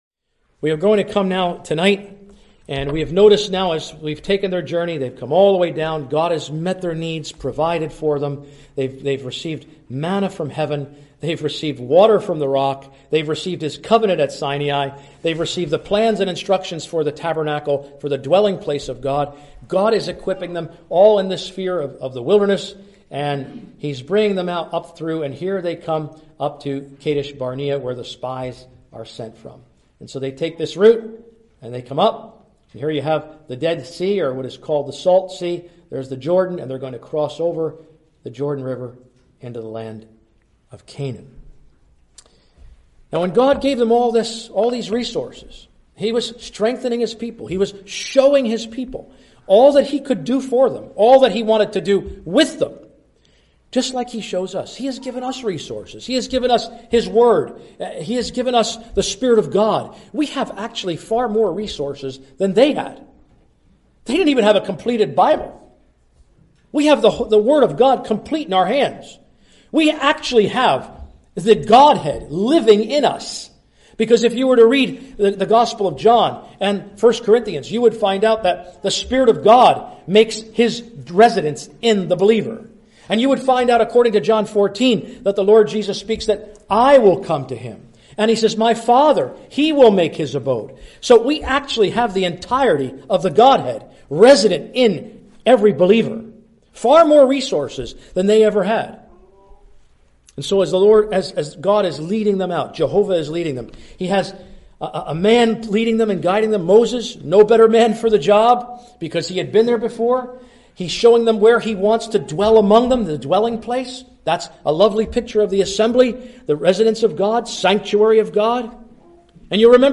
(Recorded in Marion Gospel Hall, Iowa, USA)